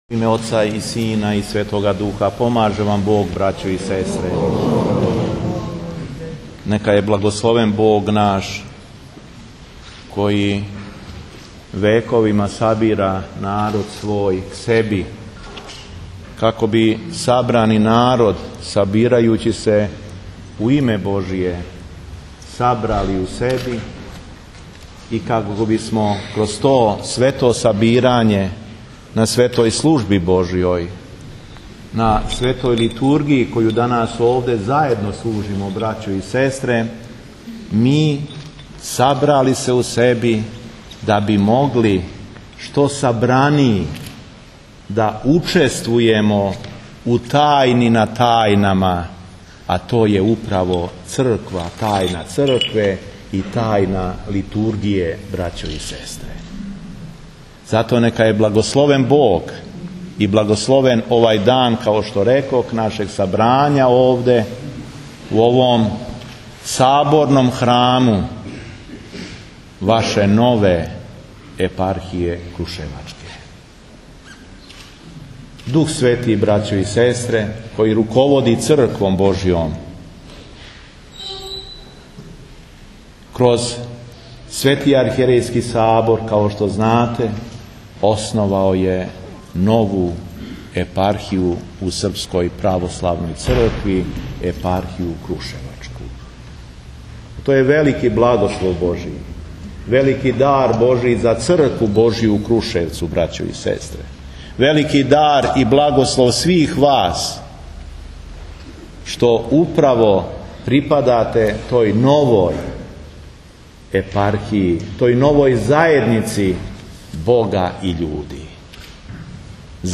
Беседа епископа шумадијског Г. Јована
У својој беседи коју је произнео после читања Светог Јеванђеља, Преосвећени Владика је заблагодарио Богу на овом сабрању, на Светој Литургији у Саборном храму крушевачке епархије. Том приликом Владика је позвао да образац нашег живљења у Цркви буде предвечни Савет Свете Тројице – Отац, Син и Дух Свети.